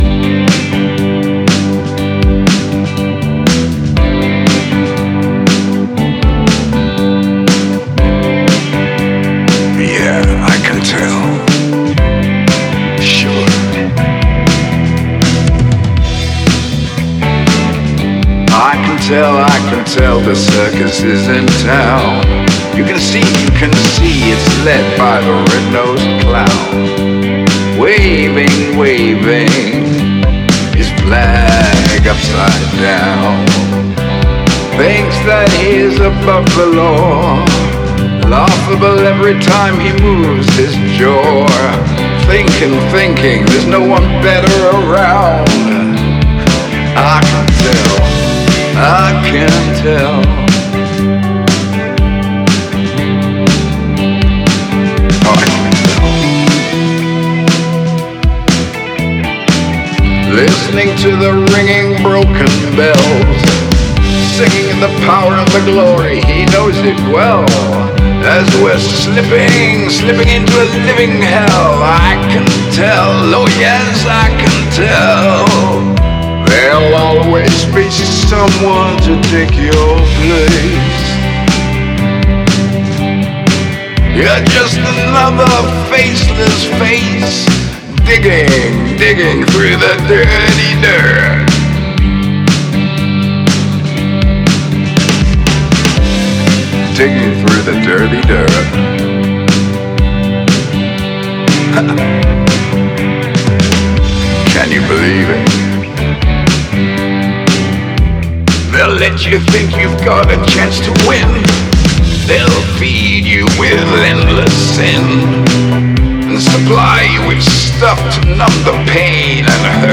Bold, uncompromising, and emotionally charged